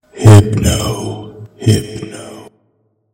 Cries
HYPNO.mp3